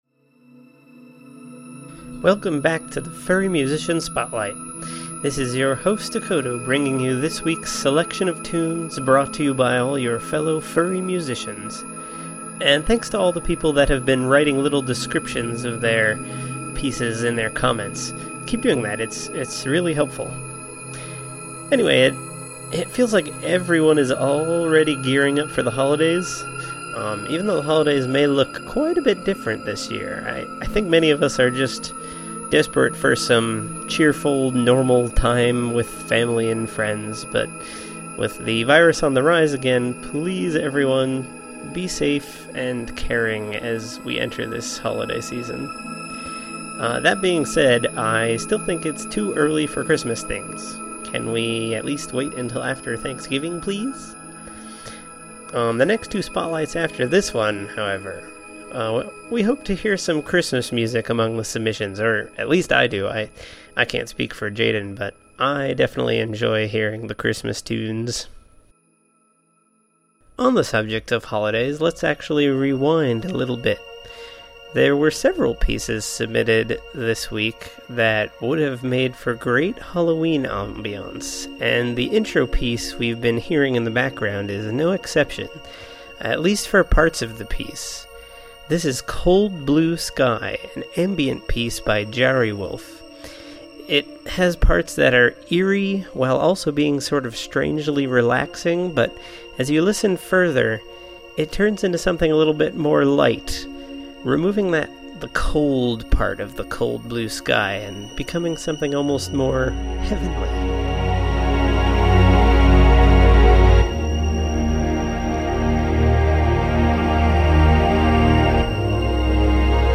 (Drum and Bass)
(Happy Hardcore)
All the sounds and instruments work together really well to create that eerie dream-like atmosphere.